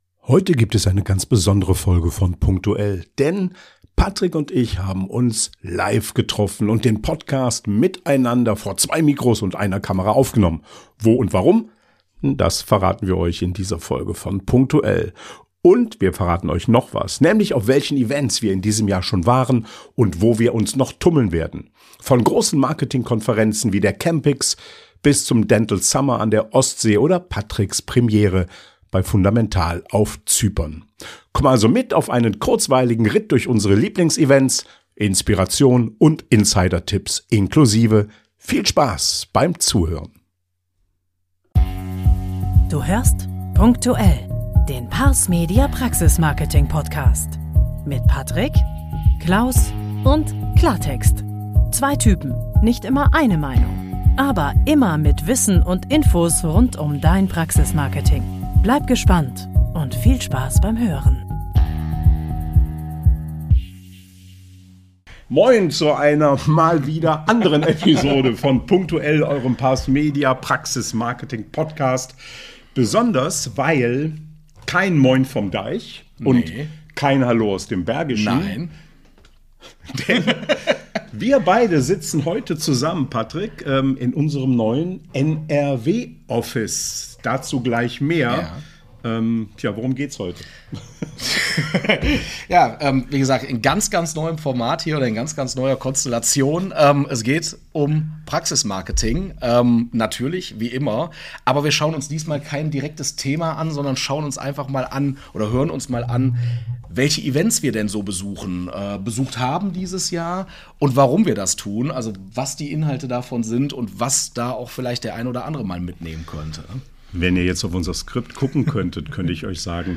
Du erfährst, welche Dental- und Marketing-Events sie besucht haben und warum Netzwerken auf Augenhöhe so entscheidend ist. Diesen Podcast haben die beiden in unserem neuen „Flying Office" bei Ador Dental in Hilden aufgenommen – einer neuen Basis für Austausch, Zusammenarbeit und Inspiration in NRW.